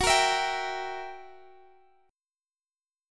F#7b9 Chord (page 2)
Listen to F#7b9 strummed